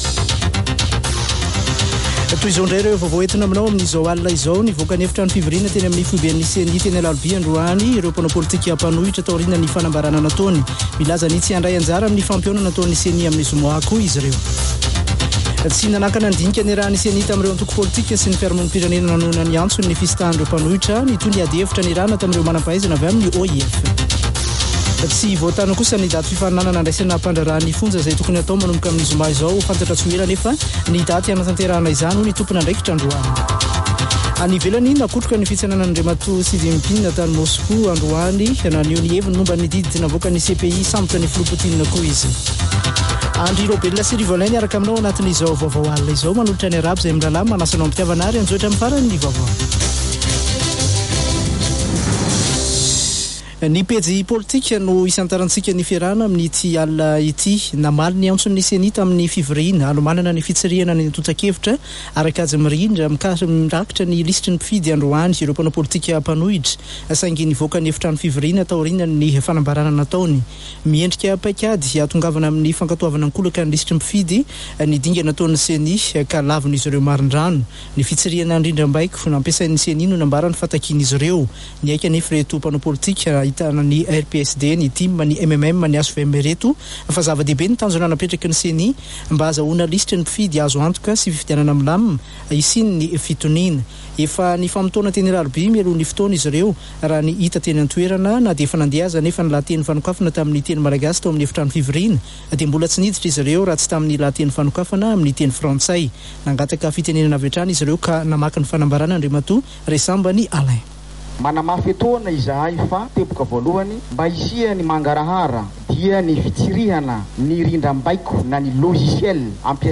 [Vaovao hariva] Alatsinainy 20 marsa 2023